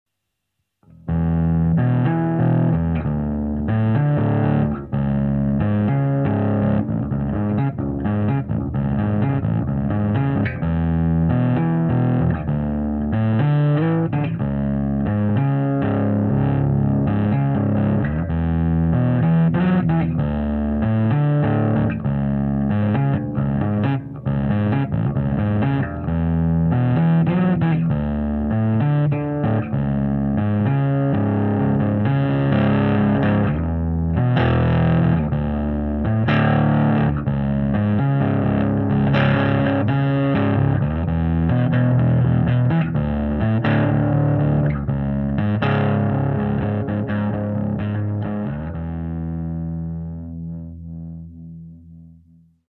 voila trois samples du preamp enregistrés avec le fostex , en reprise micro sur un 15" .aucunes retouche , sauf une minuscule pointe de reverbe avec le fostex . c'est un exemple de reglage du preamp , de memoire basse 3/4 , mid 1/2 , treble 1/4 . bright en position milieu ( son naturel ) on peut donc l'aloudir ou le rendre plus brillant .
canal disto : idem pour l'egalo gain 1/4 pour disto 1 et 1/2 pour disto 2 . bright en position milieu ( son naturel ) on peut donc l'aloudir ou le rendre plus brillant .
basse active shecter , preamp lampes , dagone 480 .
repris avec un micro akg d11 et un E906 , sur le fostex mr8 MKII .
sample_bass_disto2.MP3